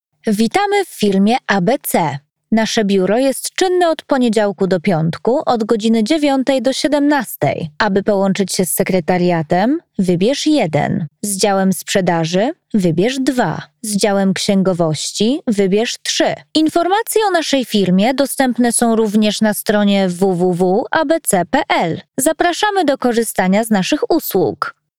Corporate Videos
YoungProfessionalFriendlyWarmPersonableBrightEnergeticUpbeat
All our voice actors record in their professional broadcast-quality home studios using high-end microphones.